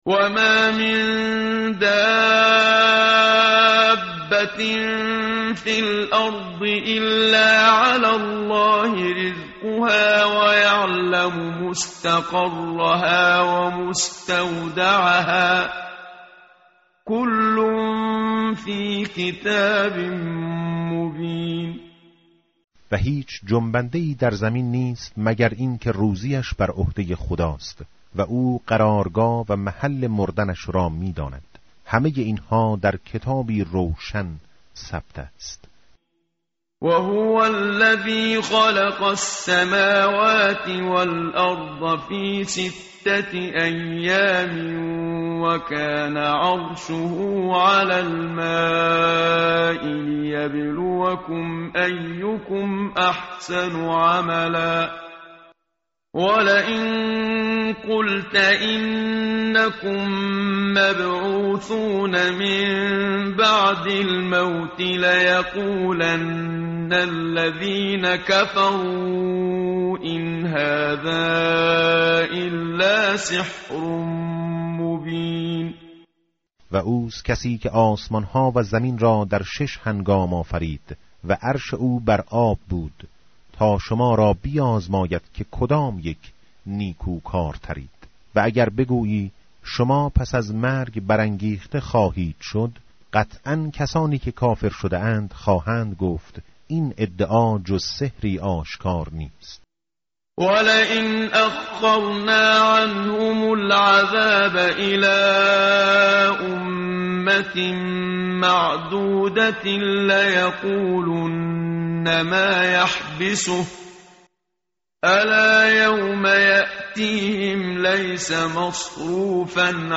tartil_menshavi va tarjome_Page_222.mp3